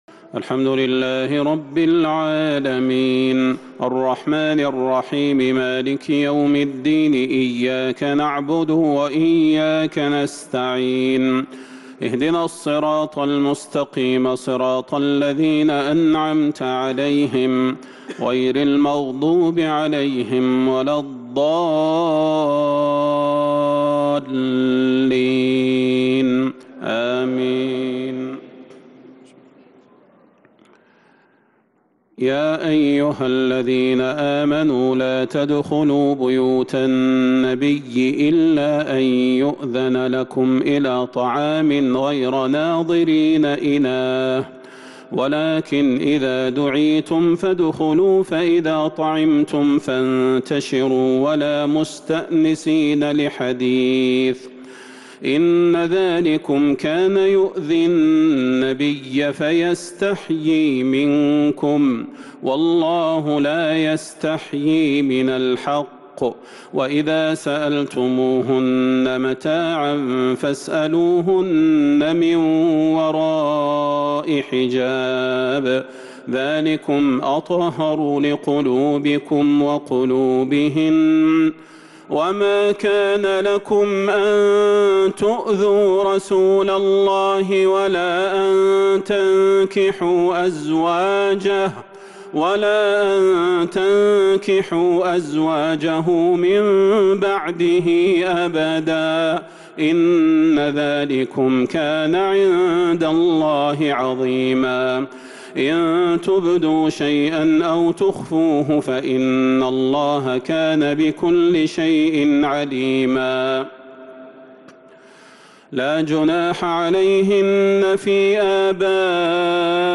تهجد ليلة 24 رمضان 1443هـ من سورتي الأحزاب و سبأ | Tahajjud 24 st night Ramadan 1443H Surah Al-Ahzaab and Saba > تراويح الحرم النبوي عام 1443 🕌 > التراويح - تلاوات الحرمين